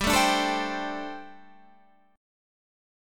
Gsus2sus4 chord